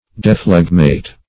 Search Result for " dephlegmate" : The Collaborative International Dictionary of English v.0.48: Dephlegmate \De*phleg"mate\, v. t. [imp.
dephlegmate.mp3